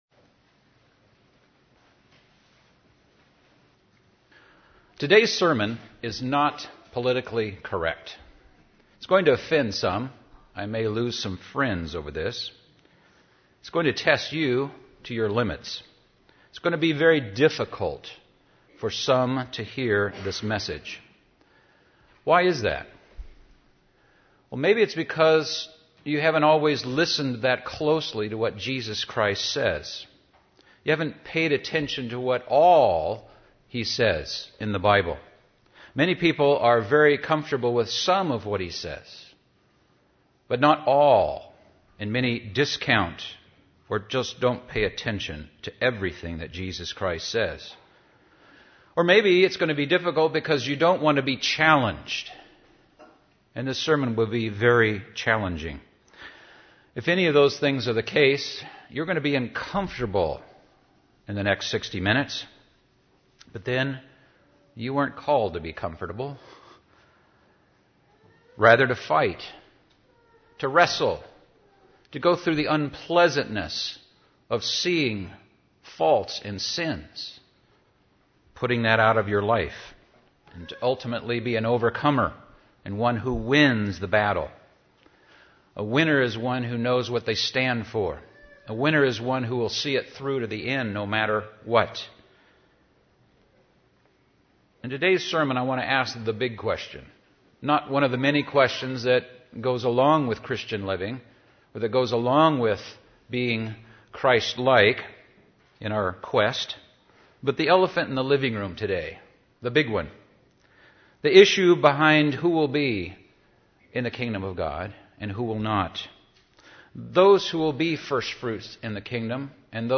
This sermon is not politically correct and it may be difficult to hear.